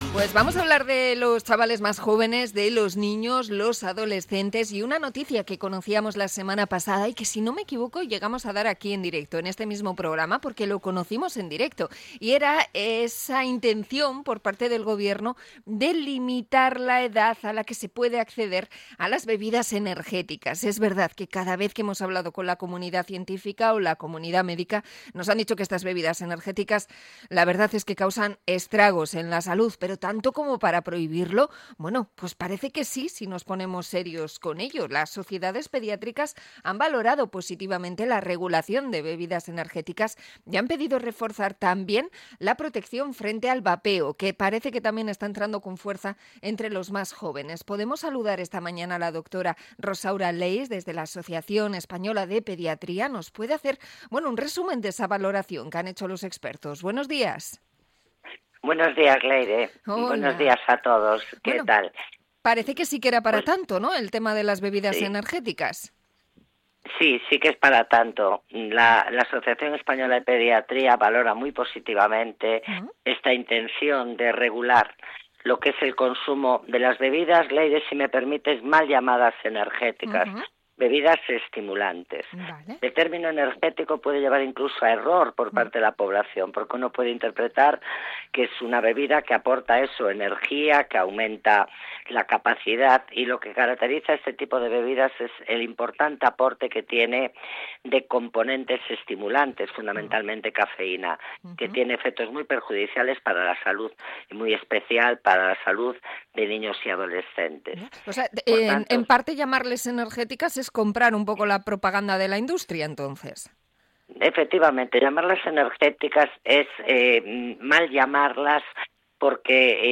Entrevista a la Asociación Española de Pediatría sobre las bebidas energéticas
La conversación se ha emitido en el programa EgunON Magazine.